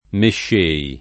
vai all'elenco alfabetico delle voci ingrandisci il carattere 100% rimpicciolisci il carattere stampa invia tramite posta elettronica codividi su Facebook mescere [ m % ššere ] v.; mesco [ m %S ko ], mesci — pass. rem. mescei [ mešš % i ]; part. pass. mesciuto [ mešš 2 to ]